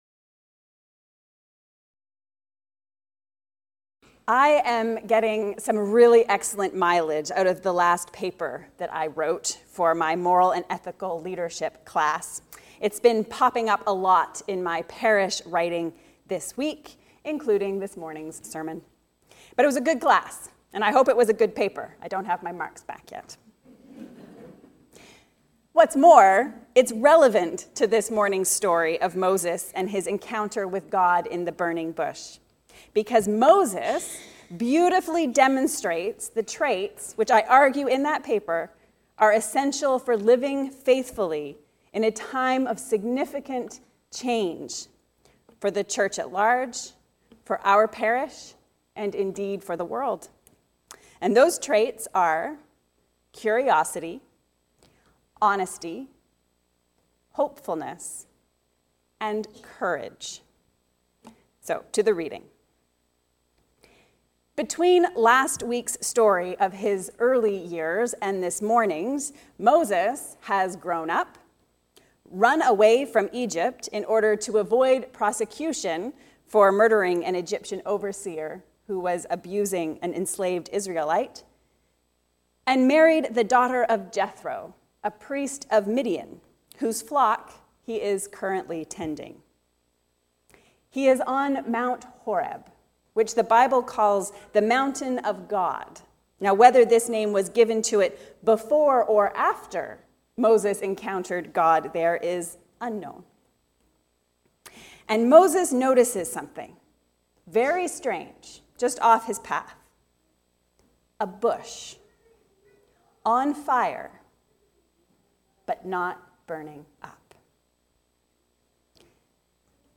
Curiousity, Honesty, Hopefulness, and Courage. A sermon on Moses at the Burning Bush